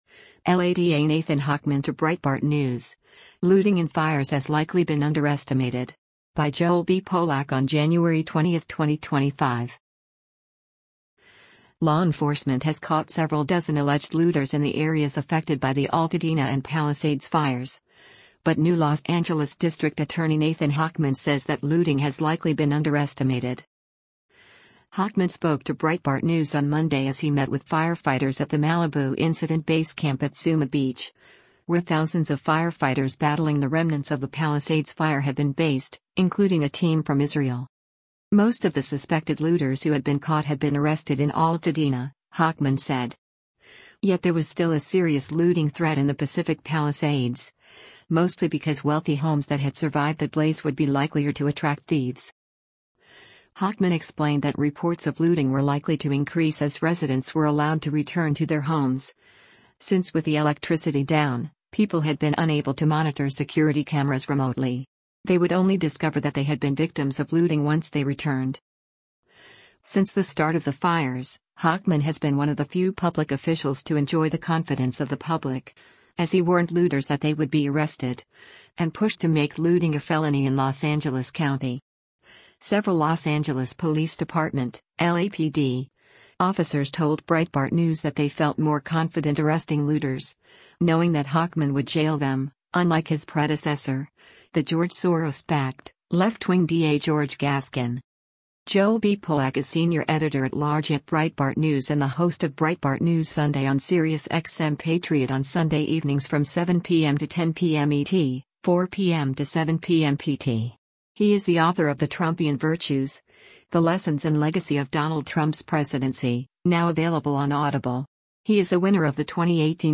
Hochman spoke to Breitbart News on Monday as he met with firefighters at the Malibu incident base camp at Zuma beach, where thousands of firefighters battling the remnants of the Palisades Fire have been based, including a team from Israel.